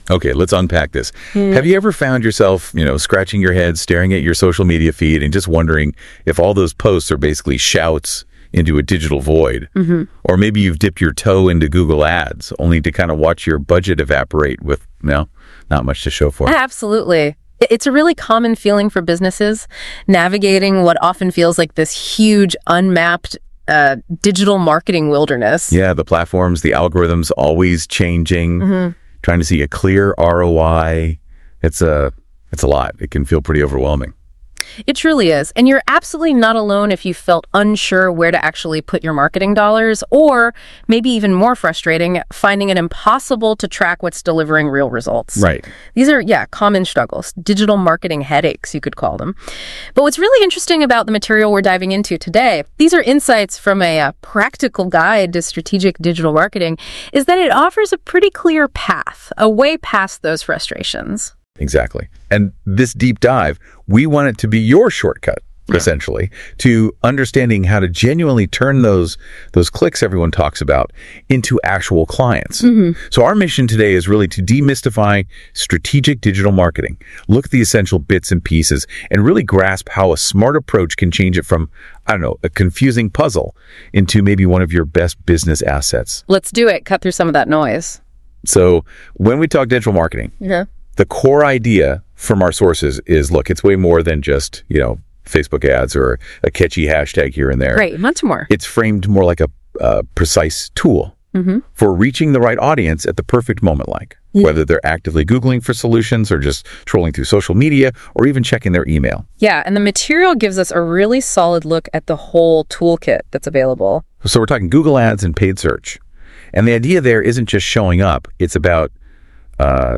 Listen to our NotebookLM Audio Deep Dive “An Introduction to the Types of Digital Marketing”. Pop in your headphones and get a no-nonsense breakdown of what works and why.